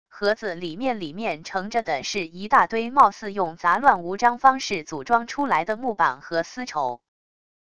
盒子里面里面盛着的是一大堆貌似用杂乱无章方式组装出来的木板和丝绸wav音频生成系统WAV Audio Player